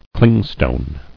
[cling·stone]